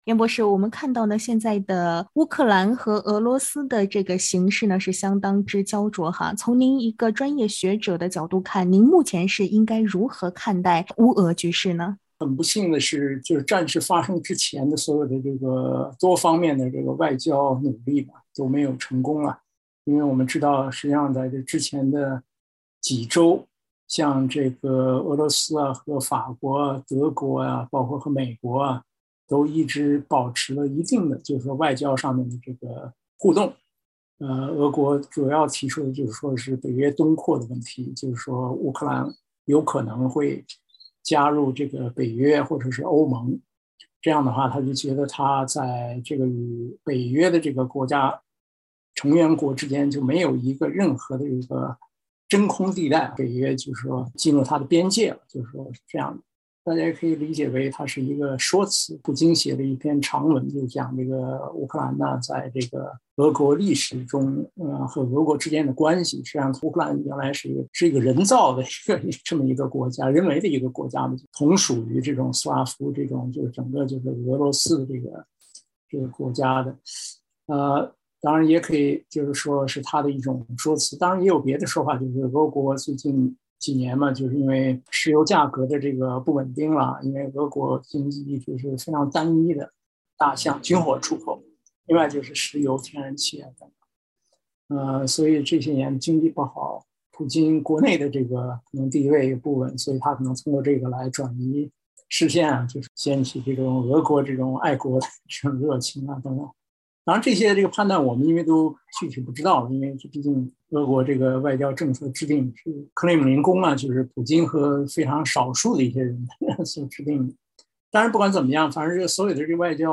（点击上图收听采访音频）